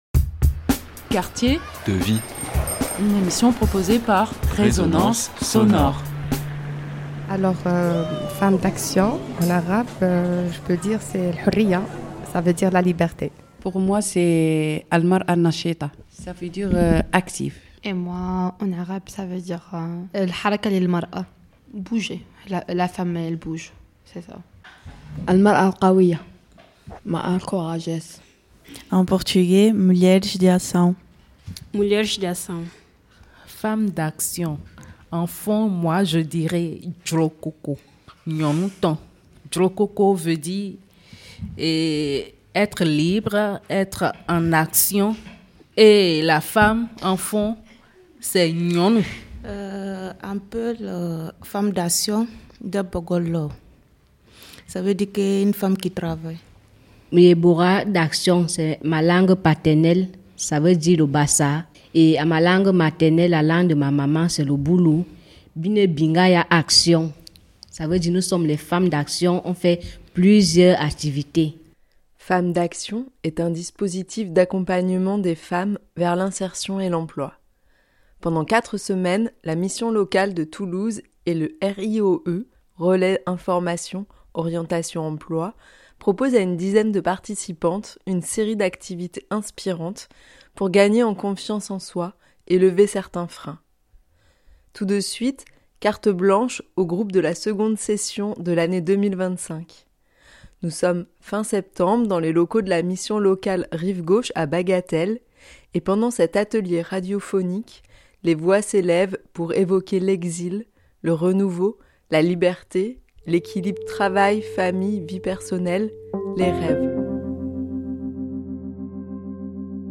« C’est la première fois », atelier radio de 9 femmes en exil